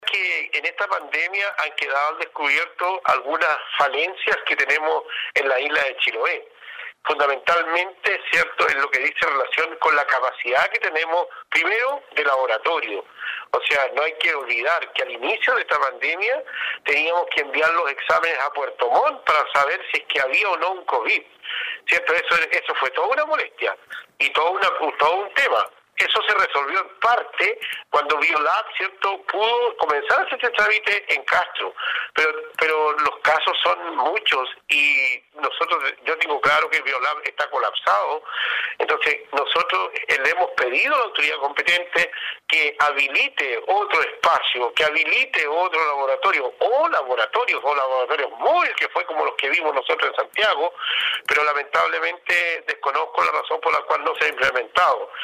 Con ello ya suman 25 confirmados, según lo dio a conocer el alcalde de la comuna, Juan Hijerra, quien se mostró muy inquieto por el gran número de contactos estrechos que se originan con estos positivos, como lo aseguró en entrevista con radio Estrella del Mar.